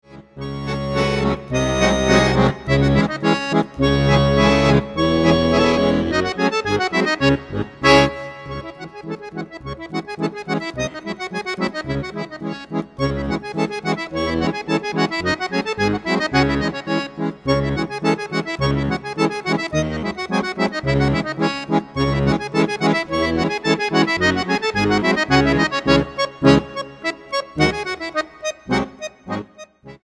acordeón clásico
vihuela y guitarra renacentistas
percusiones
guitarra española y percusiones